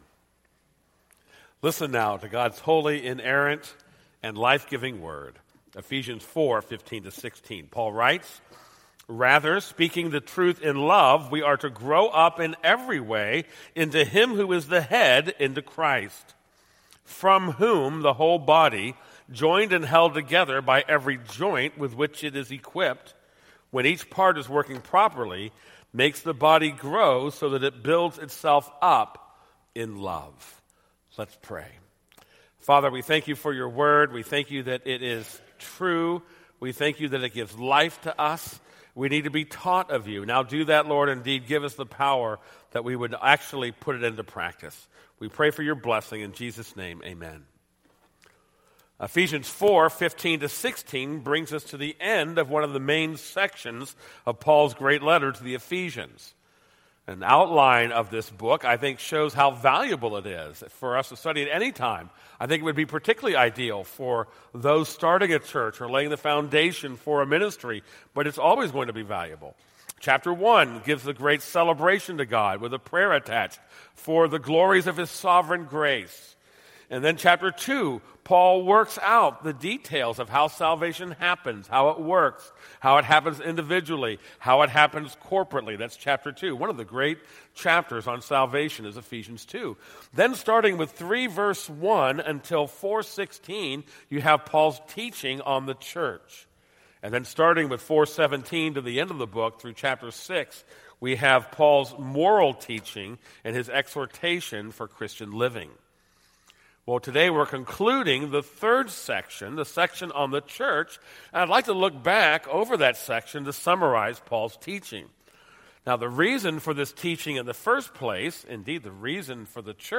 This is a sermon on Ephesians 4:15-16.